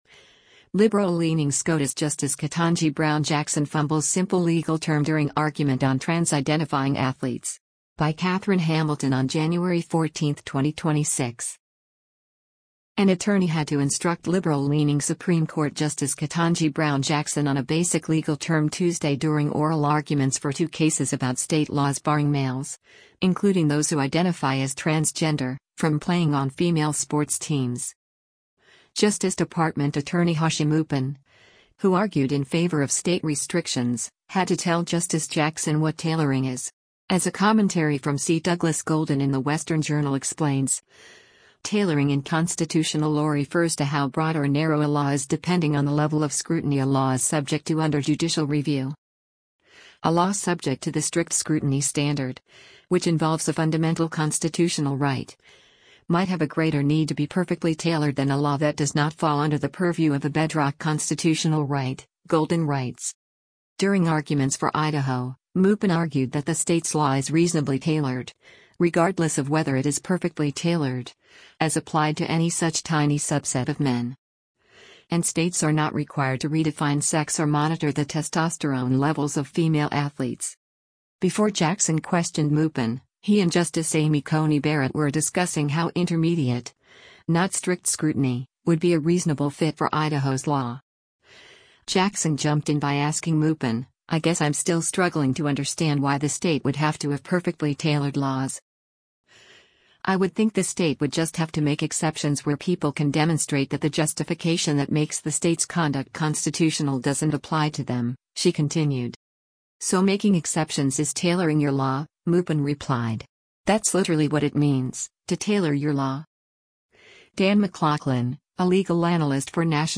Justice Ketanji Brown Jackson Fumbles Simple Legal Term During SCOTUS Arguments on Trans Athletes